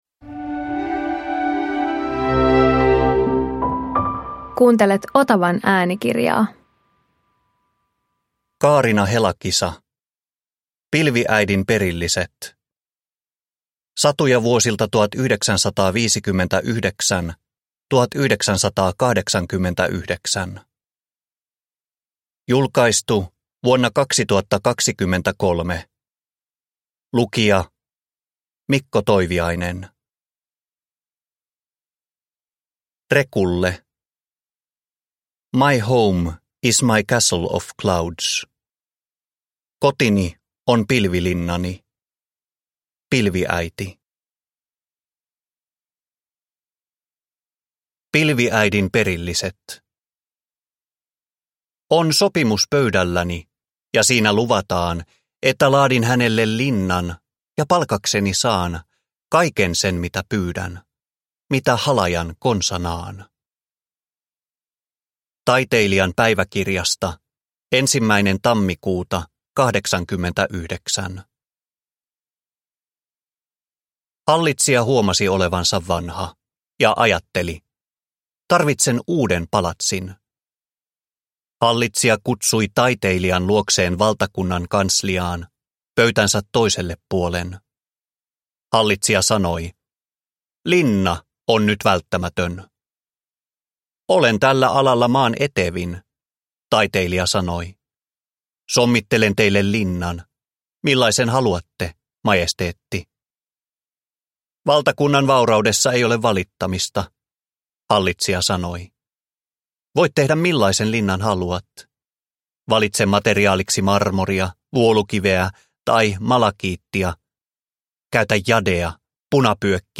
Pilviäidin perilliset (ljudbok) av Kaarina Helakisa